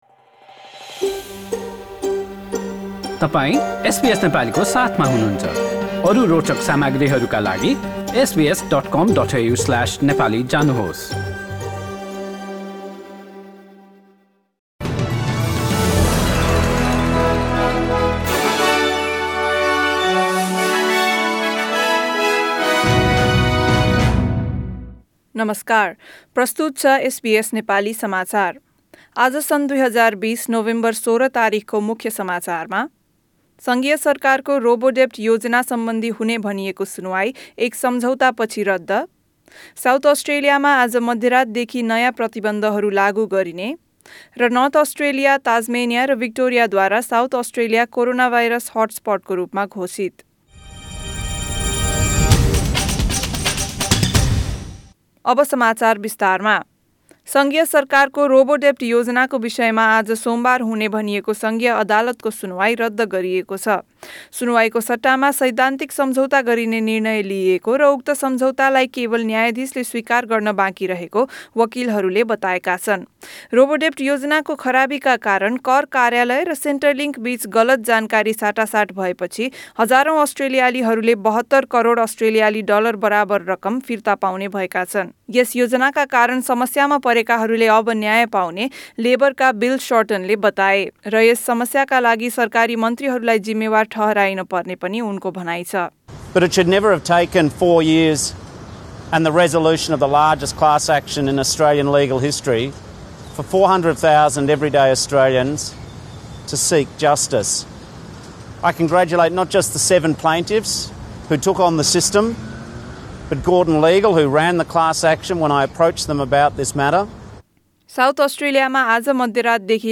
Listen to the latest news headlines in Australia from SBS Nepali radio.